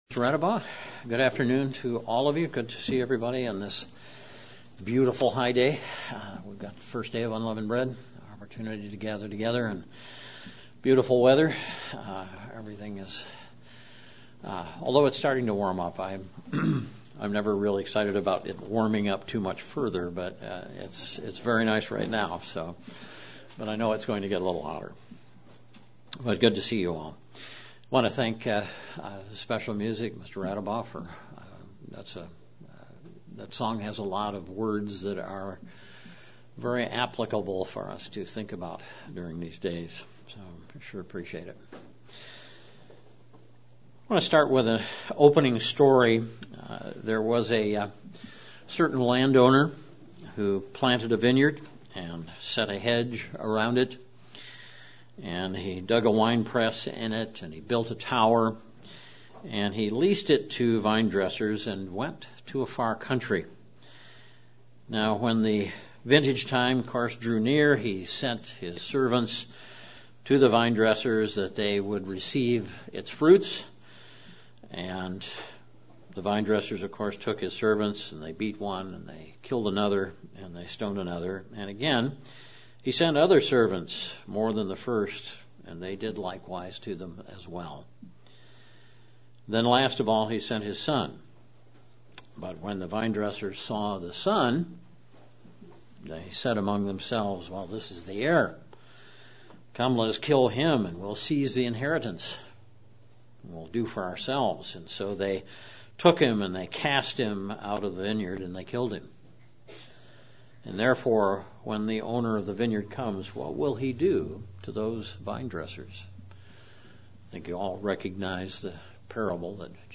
Given in Central Illinois
UCG Sermon Studying the bible?